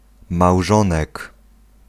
Ääntäminen
IPA : /spaʊs/